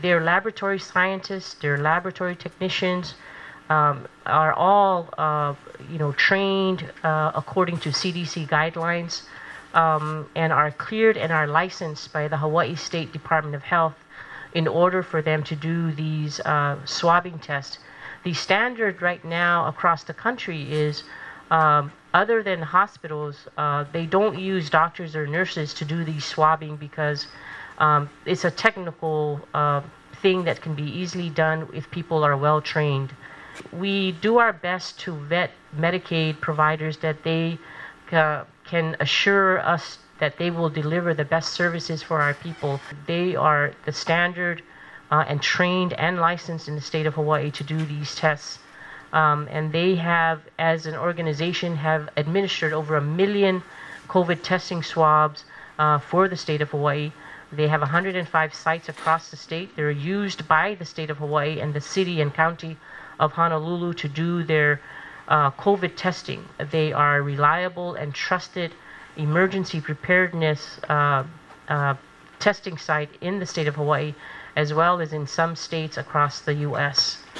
She gave details about the company at the hearing.